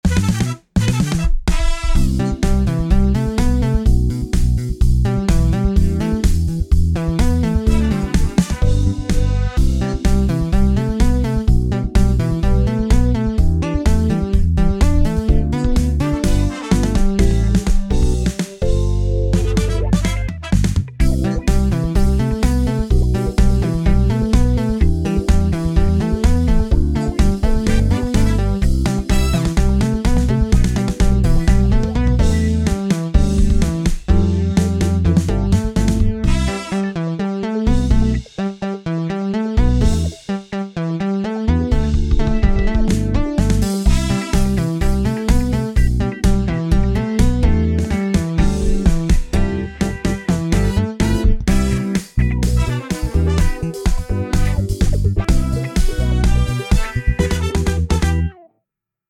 体全体で踊りたくなるようなアレンジを心がけました。
Disco風にアレンジしました。
視聴プレーヤー（インストVer.）